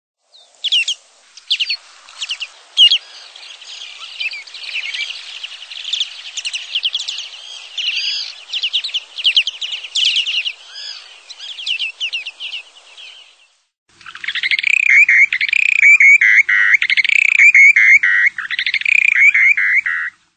Bird Sound
Flight call a melodic "tu" repeated up to four times.
Short-billedDowitcher.mp3